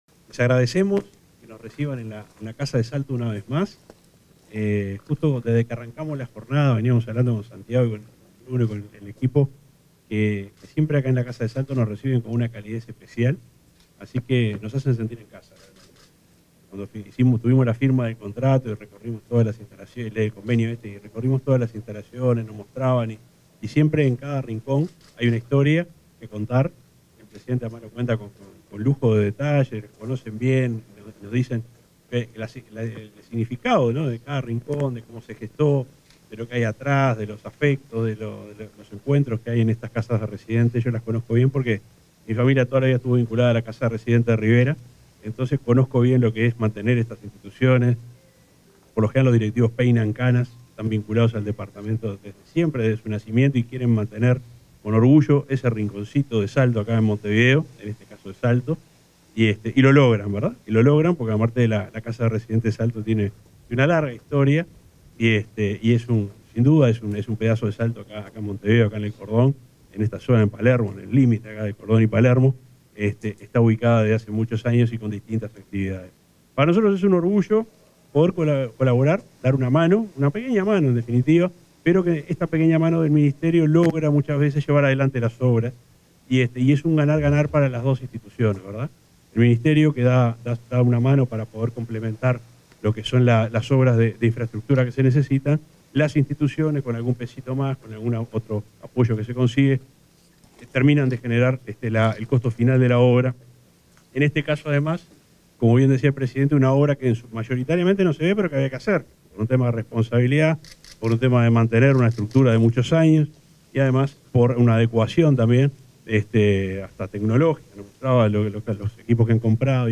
Palabras del subsecretario de Transporte y Obras Públicas, Juan José Olaizola
Palabras del subsecretario de Transporte y Obras Públicas, Juan José Olaizola 12/09/2024 Compartir Facebook X Copiar enlace WhatsApp LinkedIn En el marco de la ceremonia de inauguración de obras en Casa de Salto realizadas por convenio social, este 12 de setiembre, se expresó el subsecretario de Transporte y Obras Públicas, Juan José Olaizola.